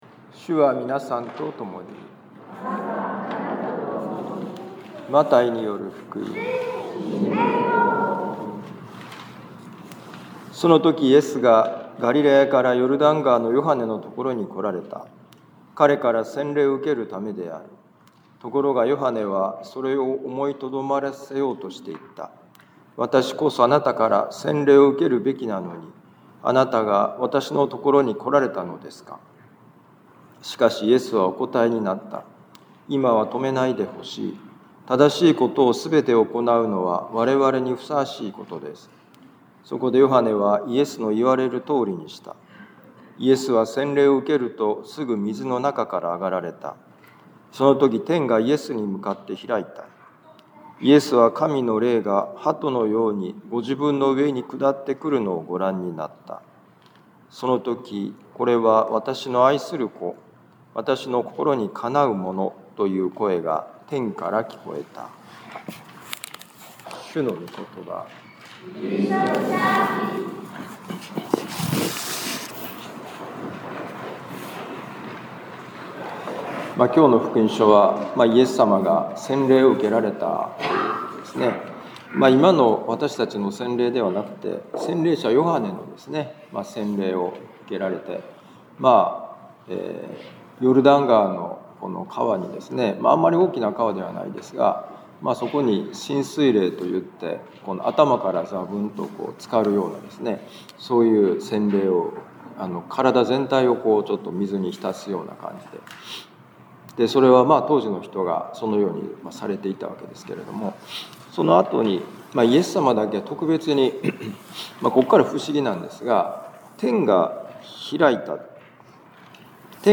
【ミサ説教】
マタイ福音書3章13-17節「天が開くとき」2026年1月11日主の洗礼のミサ 防府カトリック教会